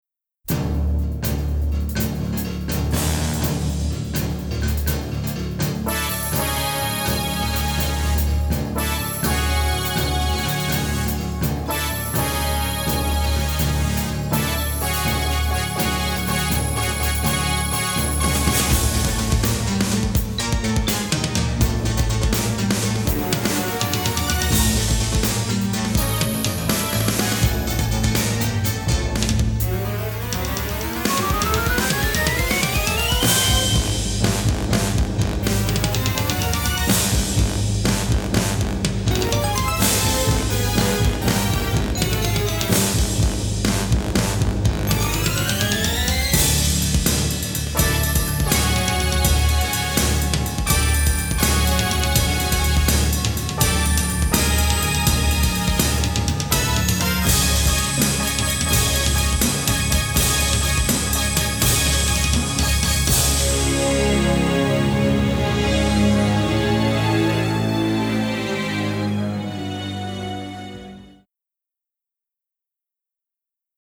sci-fi soundtrack